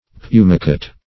Search Result for " pumicate" : The Collaborative International Dictionary of English v.0.48: Pumicate \Pu"mi*cate\, v. t. [imp.